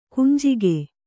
♪ kunjige